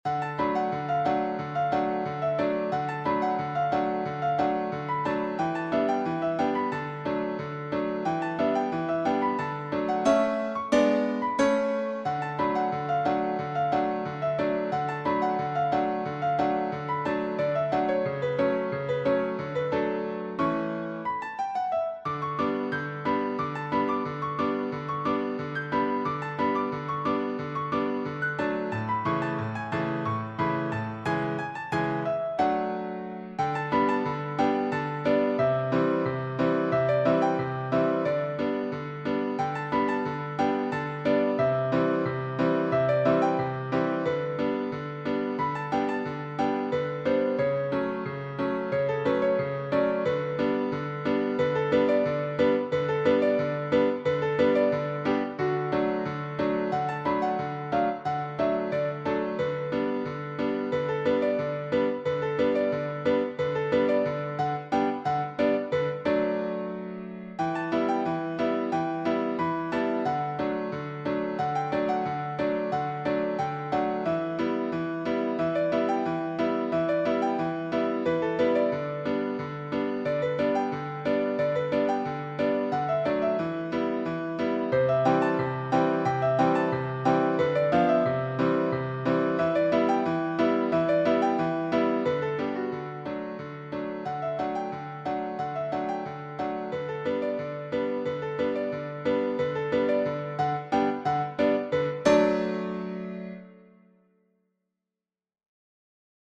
Playful
Modern ragtime
Piano only